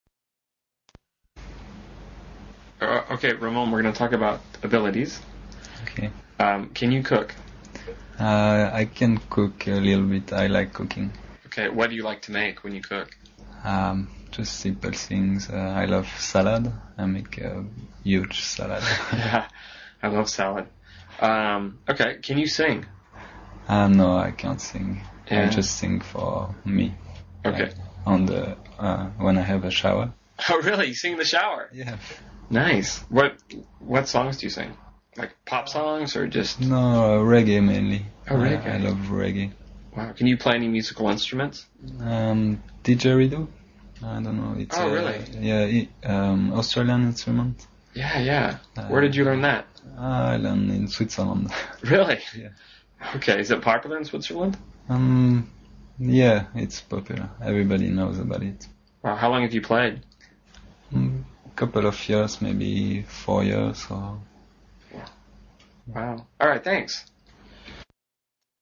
英语高级口语对话正常语速23:能力（MP3）